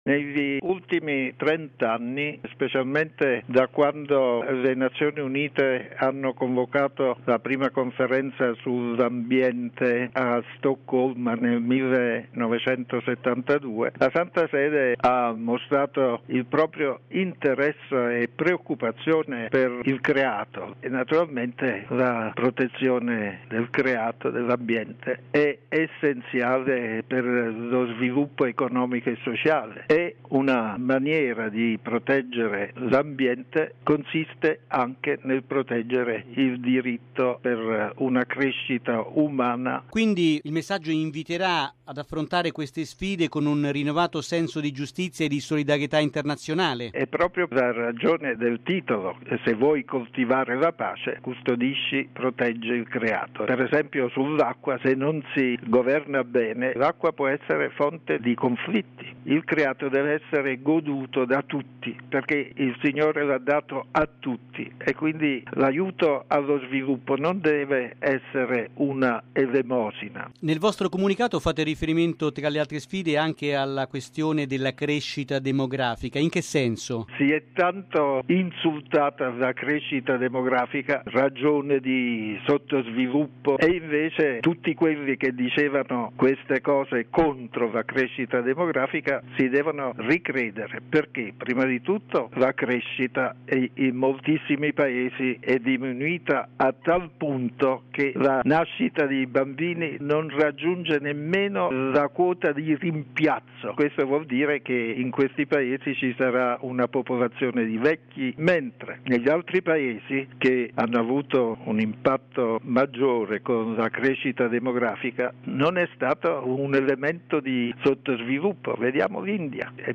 "Se vuoi coltivare la pace, custodisci il creato": il tema del messaggio del Papa per la Giornata Mondiale della Pace 2010. Intervista col cardinale Martino